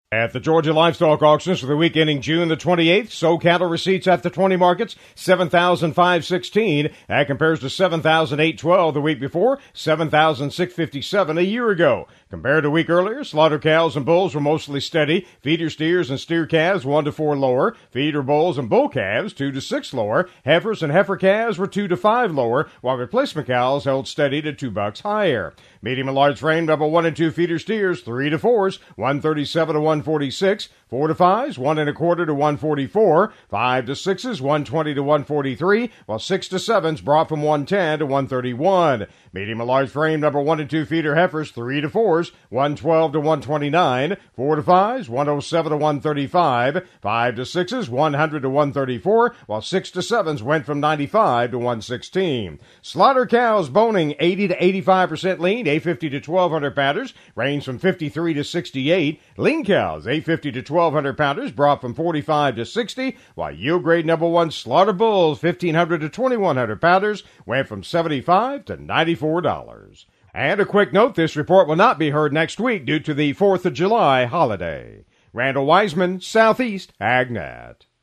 GA Livestock Market Report: